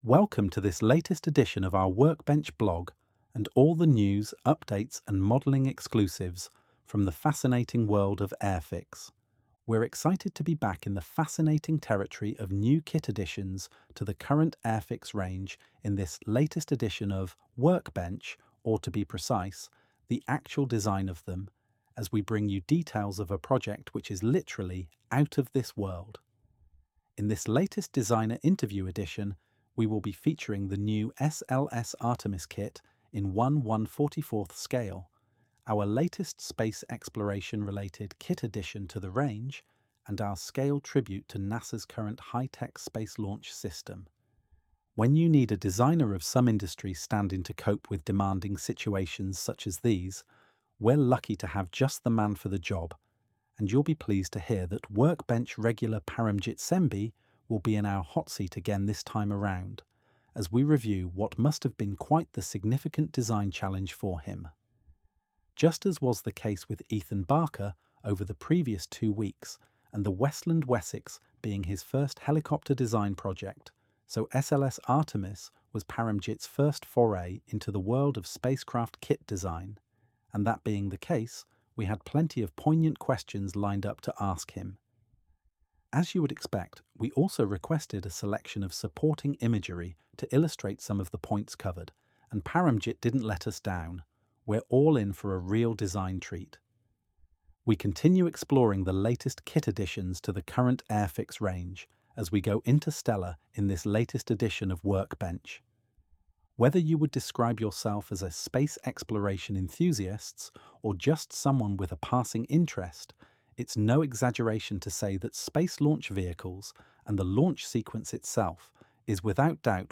🛠 Exclusive Insights – Hear firsthand from Airfix designers and developers about the process behind your favourite kits, ✈ Model Spotlight – Explore the story of each release, whether it’s a revived classic